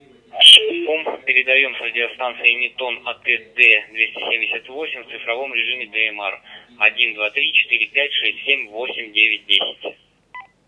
Профессиональная цифровая радиостанция стандарта DMR.
Пример модуляции (передачи) радиостанции AT-D278 в цифровом режиме DMR:
anytone-at-d278-tx-dmr.wav